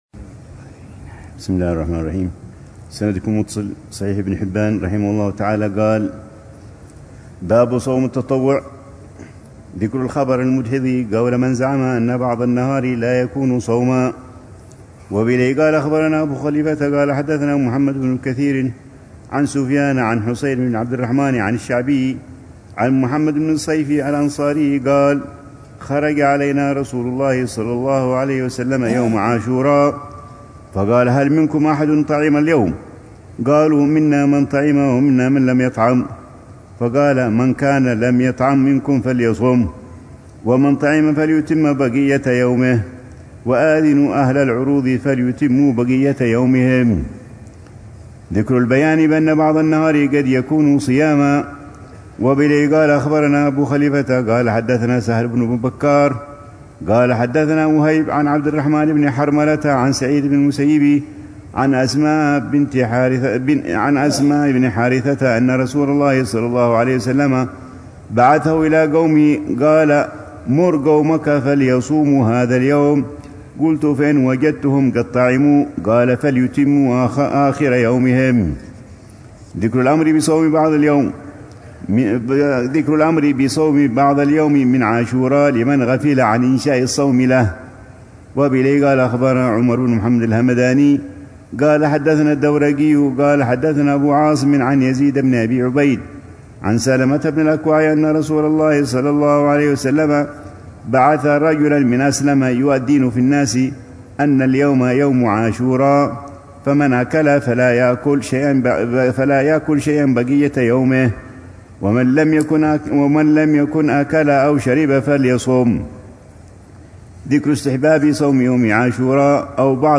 الروحة الثلاثون بدار المصطفى أيام الست من شوال لعام 1446هـ ، وتتضمن شرح الحبيب العلامة عمر بن محمد بن حفيظ لكتاب الصيام من صحيح ابن حبان، وكتا